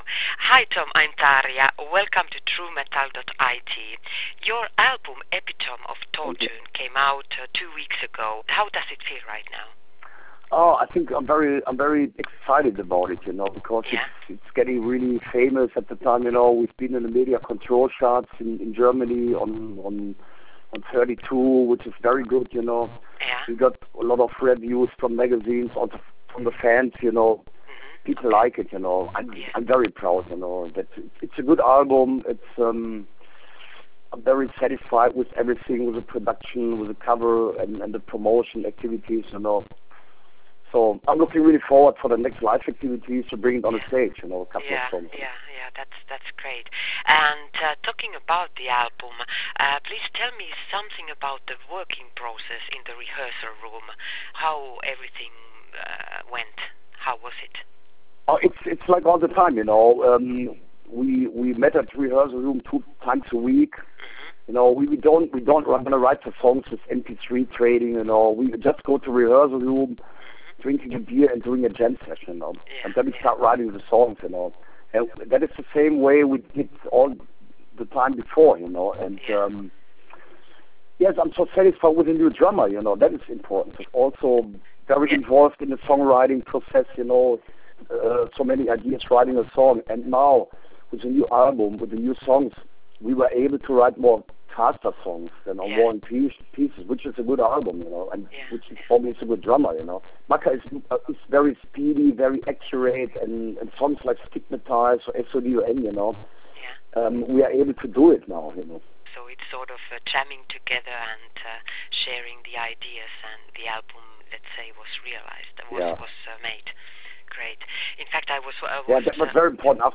Audio Interview With SODOM: Tom Angelripper Talks About Feelings After The Release Of “Epitome Of Torture”